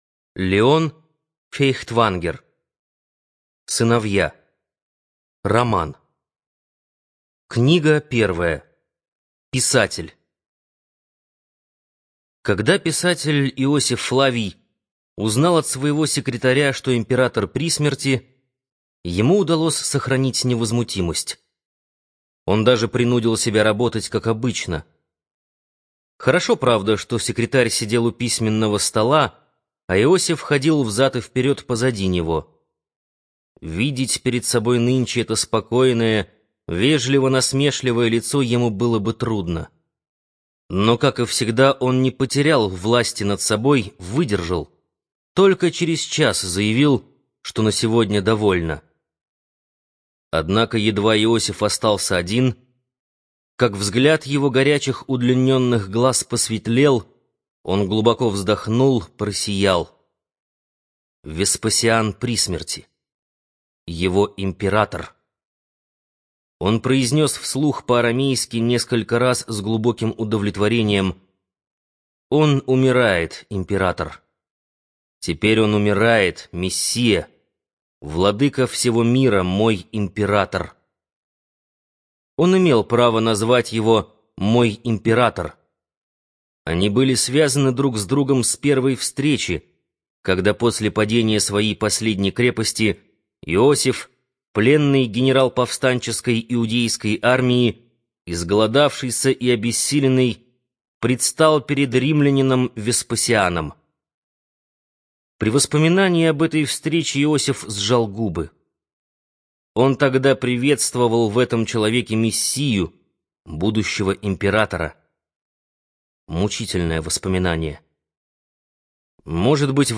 ЖанрИсторическая проза
Студия звукозаписиБиблиофоника